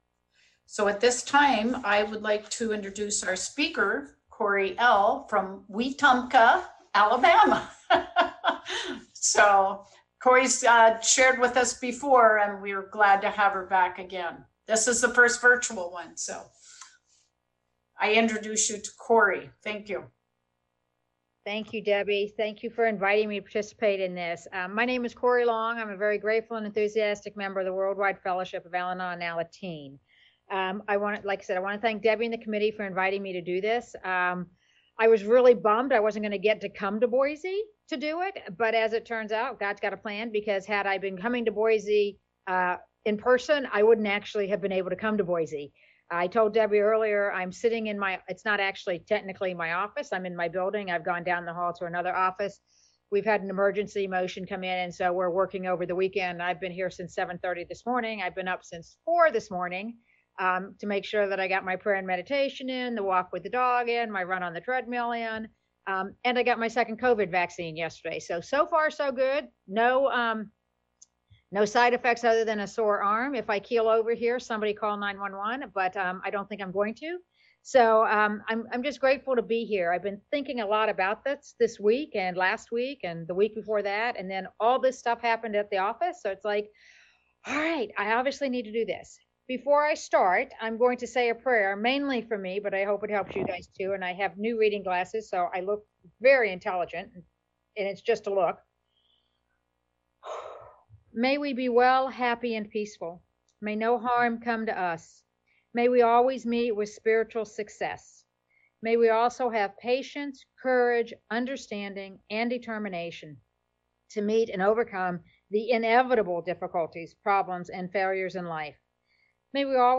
Idaho District 3 Fundraiser - Al-Anon Steps Come Alive